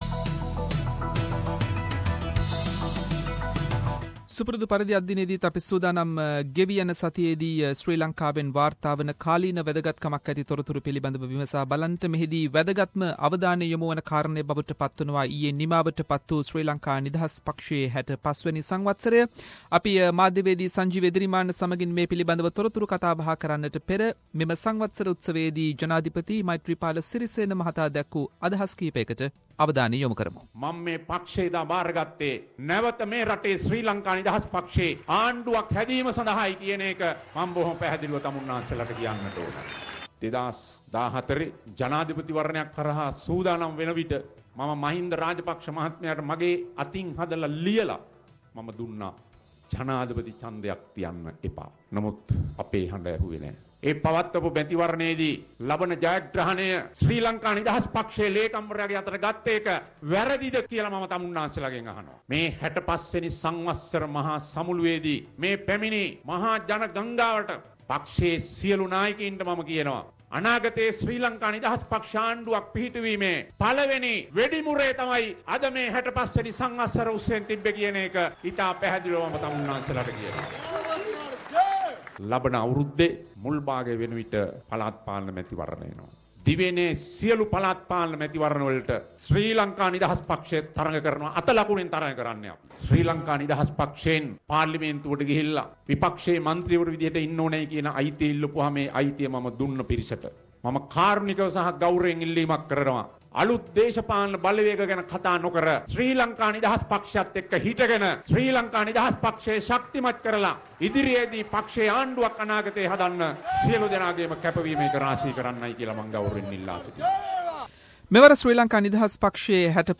SBS Sinhalese weekly Sri Lankan news wrap – Sri Lankan President vows to make party strong at SLFP 65th convention